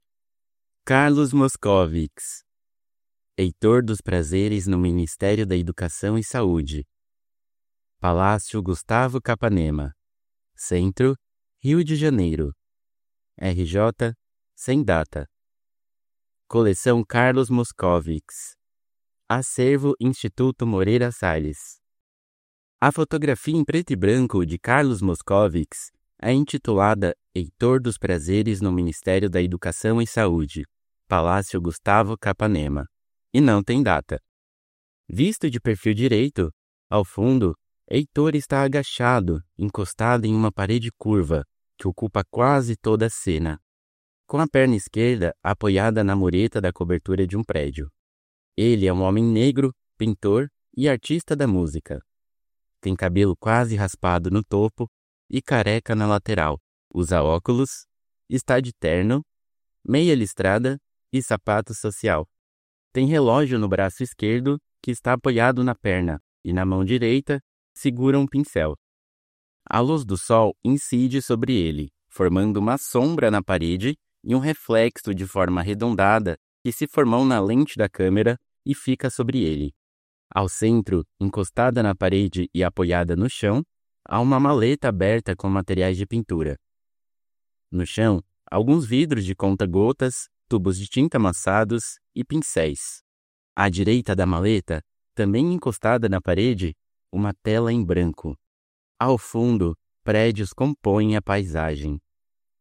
- Audiodescrições | Heitor dos Prazeres no Ministério da Educação e Saúde, de Carlos Moskovics - Instituto Moreira Salles